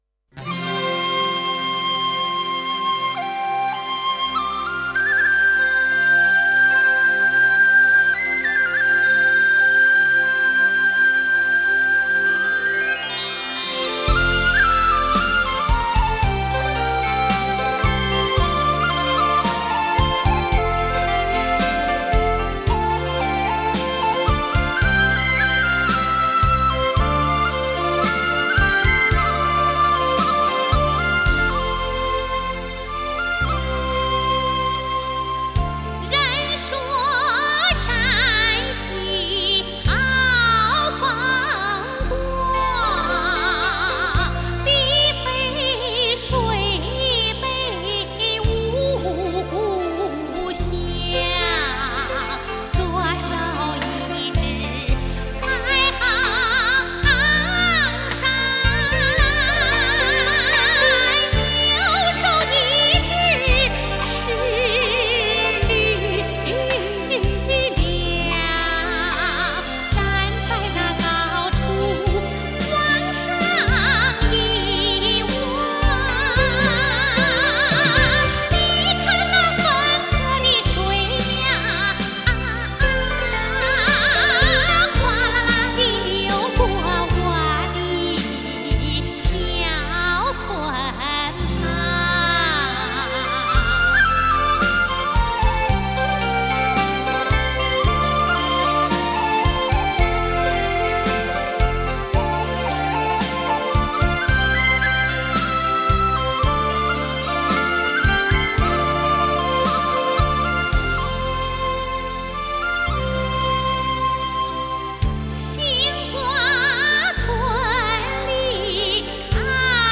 Жанр: Chinese pop ∕ Chinese folk